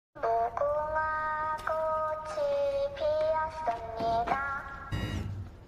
Listen and download the Squid Game Doll meme sound effect button.